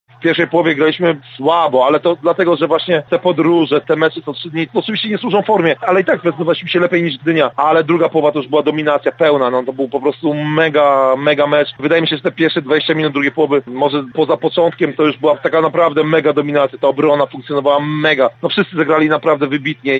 Mówił nam po meczu w Gdyni